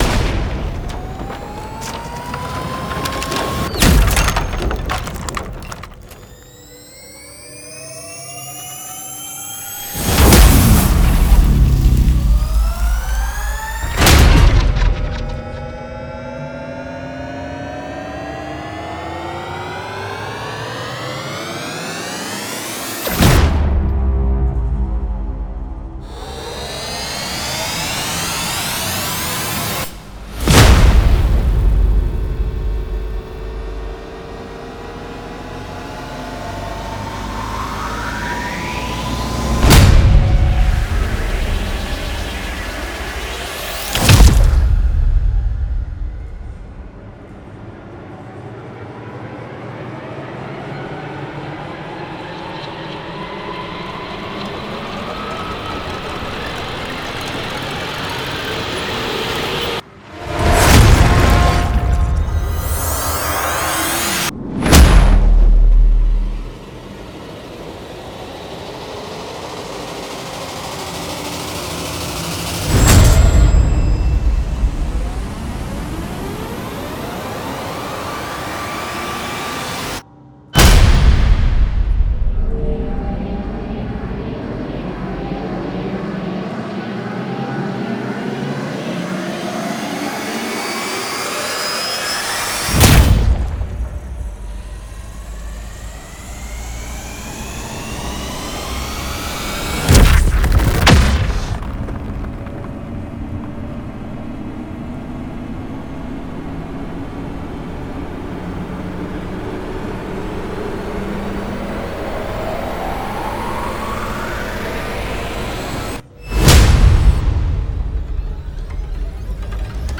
Genre:Cinematic
本作は緊張感の構築にさらに踏み込み、より長いビルドアップ、鋭いトランジェント、重厚なダウンビート、そして攻撃的なハイブリッドレイヤリングを特徴としています。
各サウンドは、微細な大気的動きから力強く決定的なインパクトへと進化し、トランジション、カット、リビール、クライマックスシーンを引き上げるようデザインされています。
昇降するトーンのライザー
機械的かつシンセティックなビルドアップ
オーケストラの緊張感あるクレッシェンド
ダークなハイブリッドスウェル
巨大なシネマティックダウンビート
グリッチを用いた加速
攻撃的なインパクト着地
50 Designed Rise & Hits